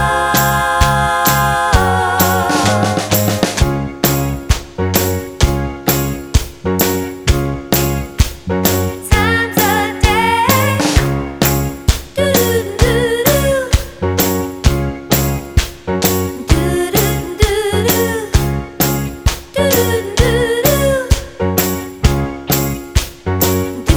With Chord No Backing Vocals Soul / Motown 2:46 Buy £1.50